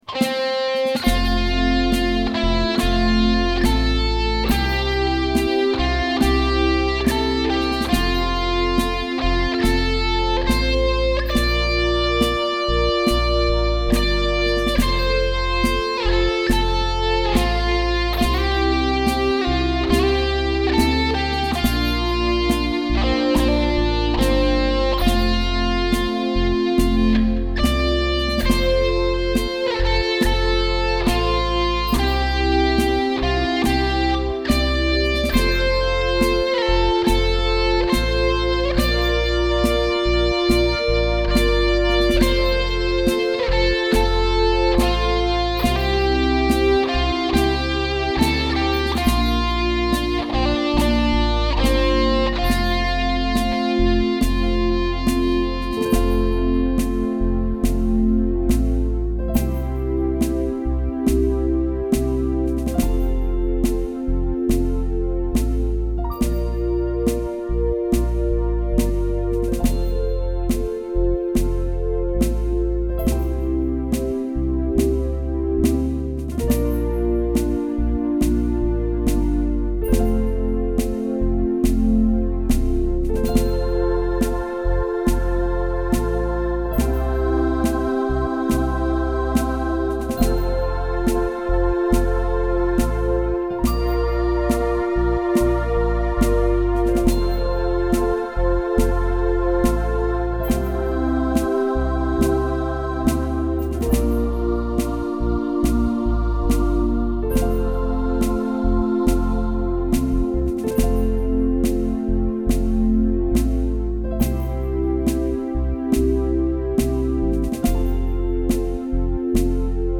I played the vocal melody on guitar for the first verse and chorus, but omitted it on the next 5 verses and choruses to allow for vocals.
Here's my original recording without vocals: